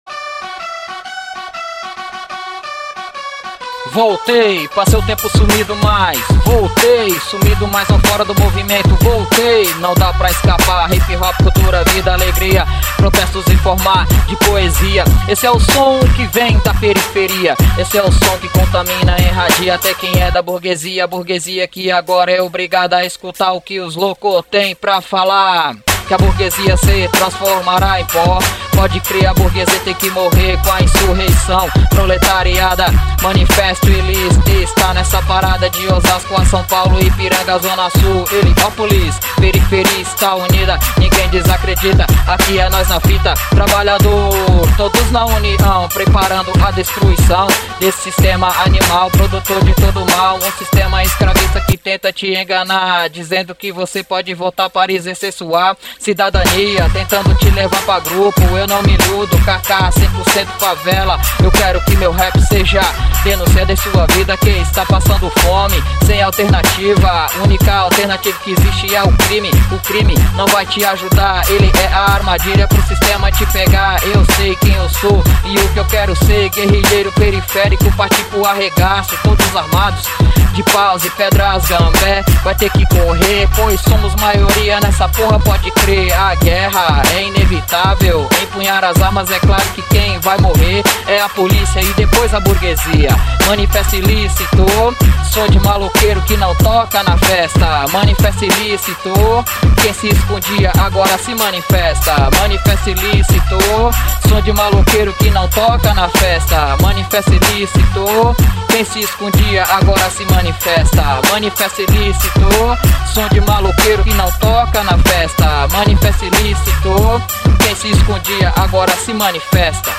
Rap clasista desde Brasil
Obrero metalúrgico de Brasil nos envía estos rap clasistas por whatsapp